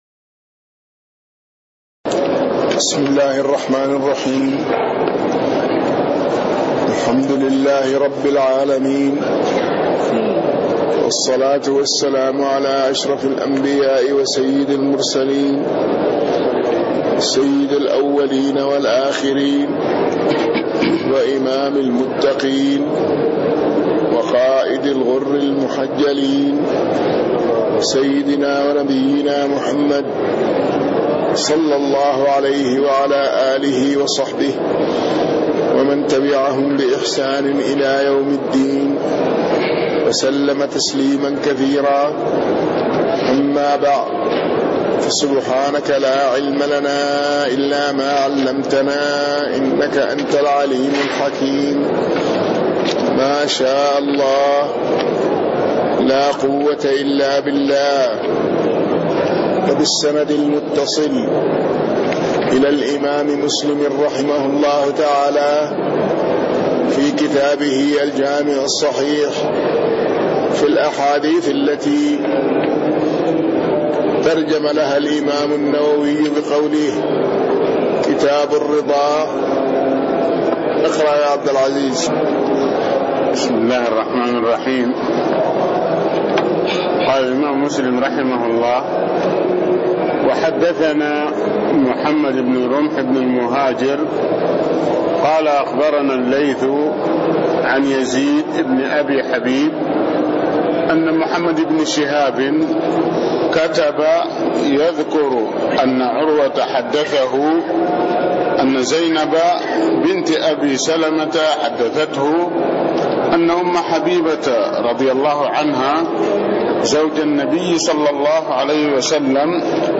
تاريخ النشر ١٩ رجب ١٤٣٤ هـ المكان: المسجد النبوي الشيخ